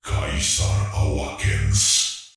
Subject description: Some highly electronic hero unit voice resources!
These voices were self generated by me, and I carried out a series of complex follow-up work to make them highly electronic and magnetic.